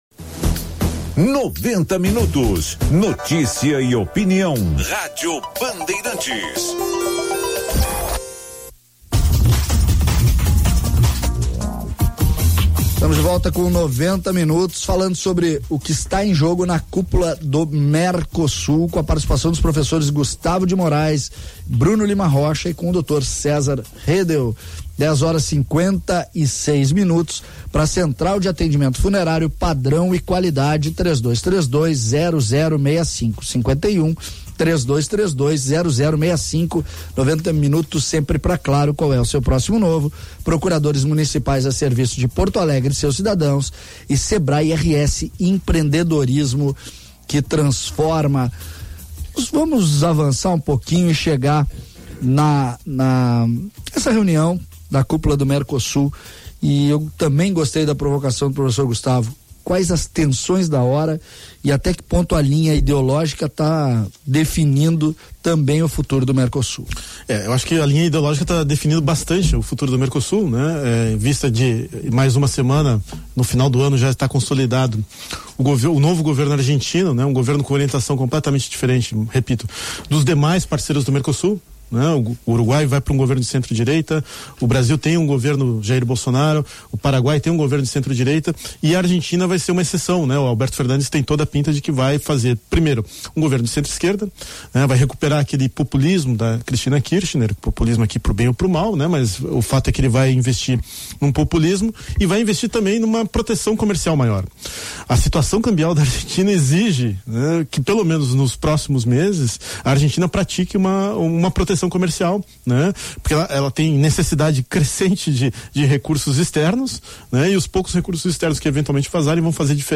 Bloco 3| Debate sobre a C�pula do Mercosul em Bento Gon�alves